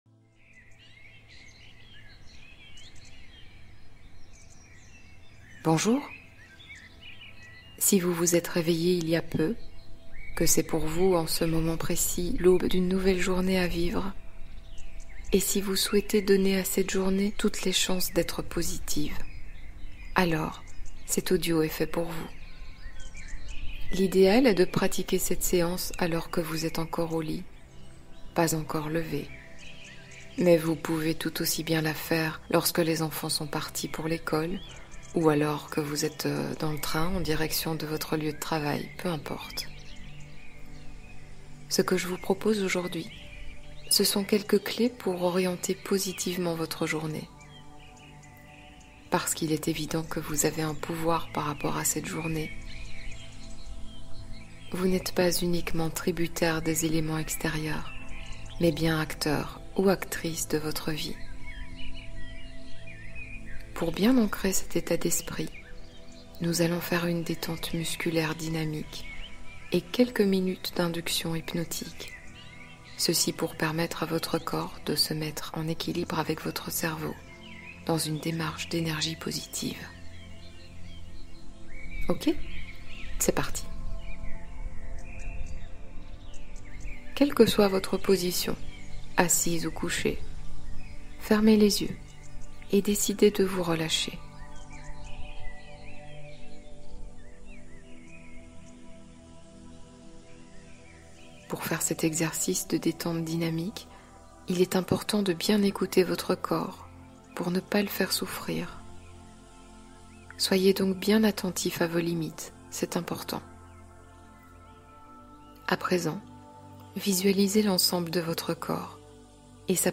Attirer l’amour : hypnose pour ouvrir le cœur